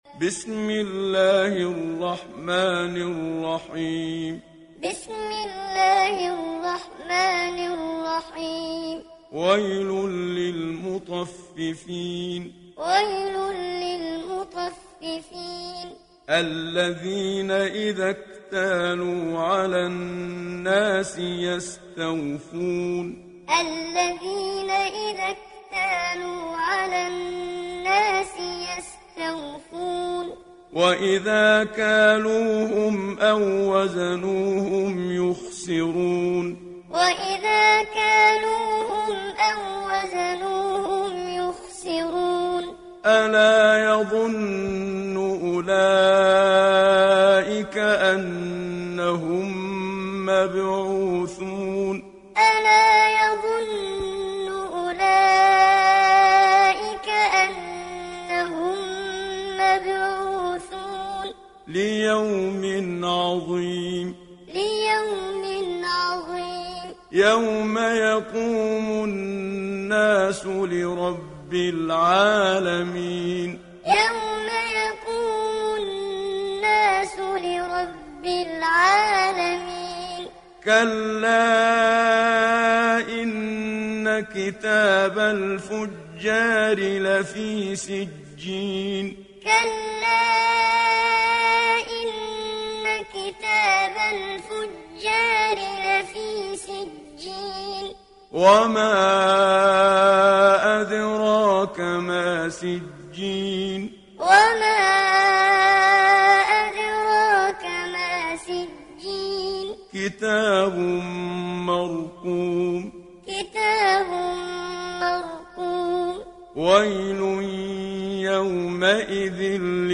تحميل سورة المطففين mp3 بصوت محمد صديق المنشاوي معلم برواية حفص عن عاصم, تحميل استماع القرآن الكريم على الجوال mp3 كاملا بروابط مباشرة وسريعة
تحميل سورة المطففين محمد صديق المنشاوي معلم